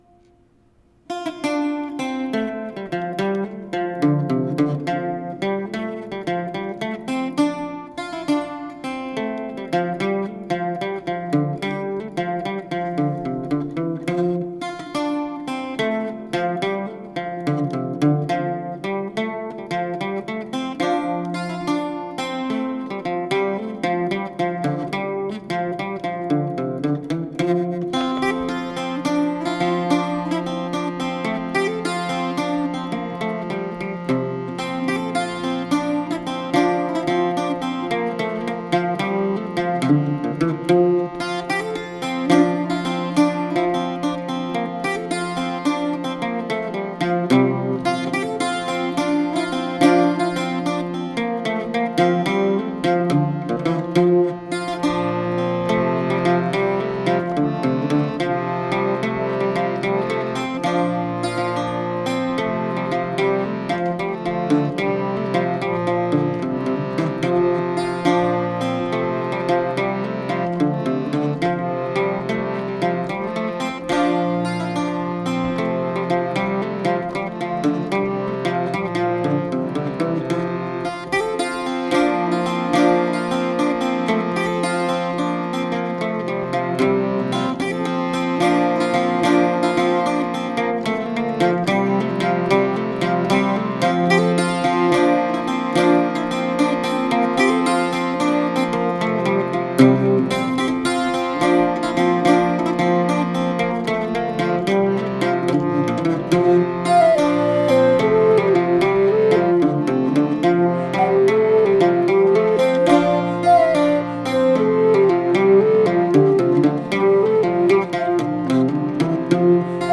A waltz I wrote and recorded on Irish bouzouki, low whistle and harmonium.